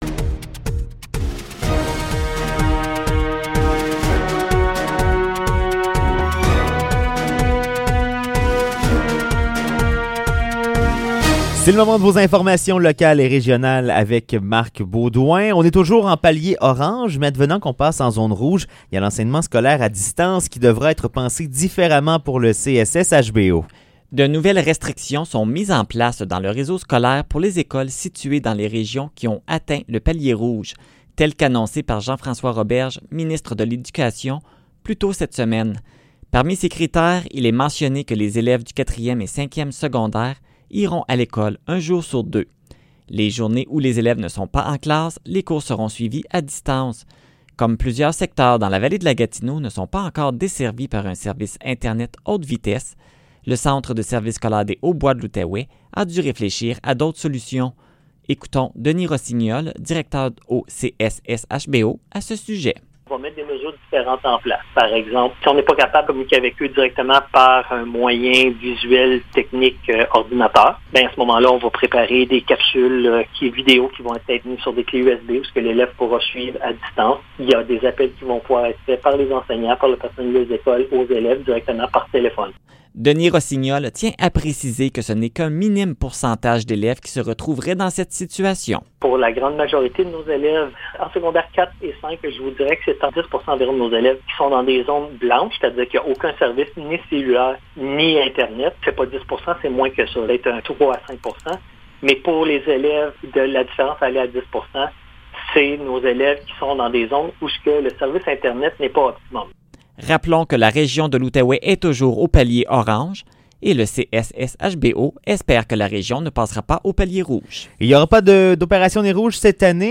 Nouvelles locales - 9 octobre 2020 - 16 h